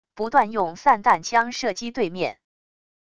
不断用散弹枪射击对面wav音频